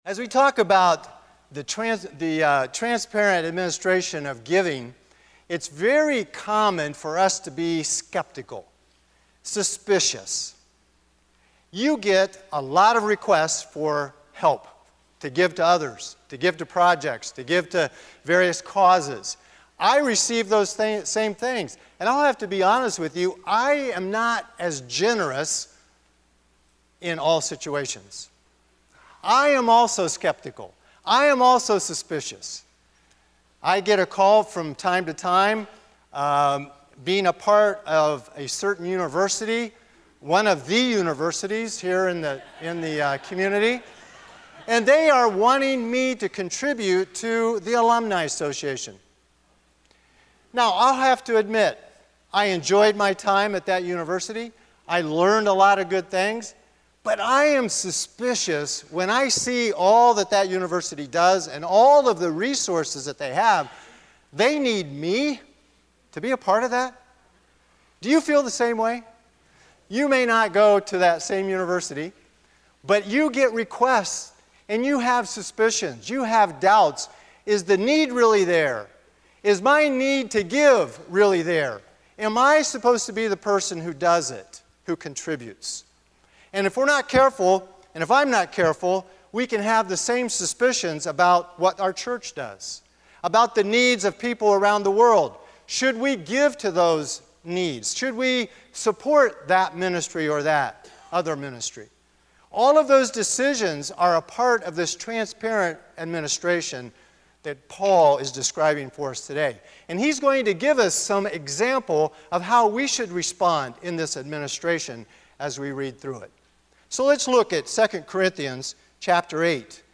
A sermon from the series "Transparent."